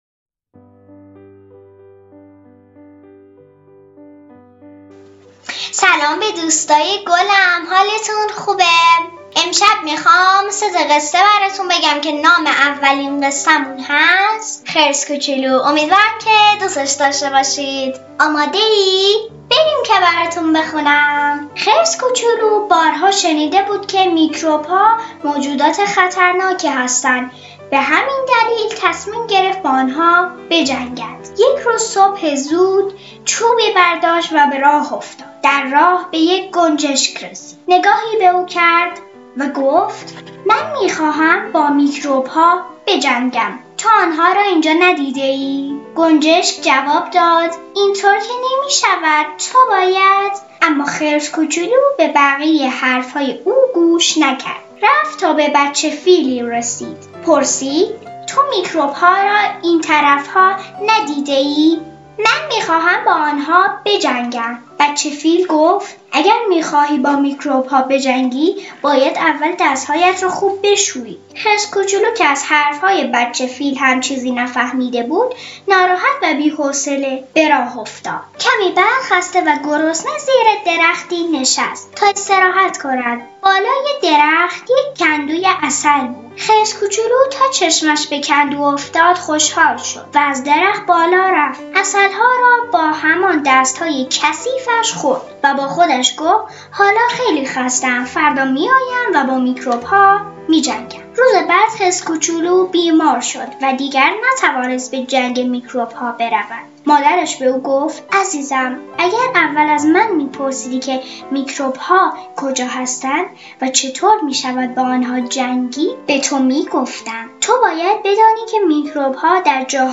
قصه صوتی